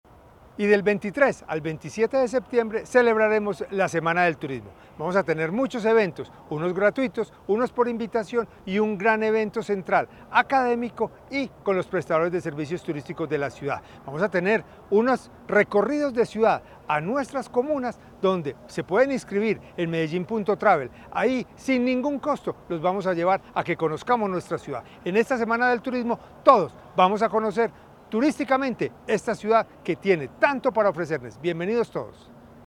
Palabras de José Alejandro González, secretario de Turismo y Entretenimiento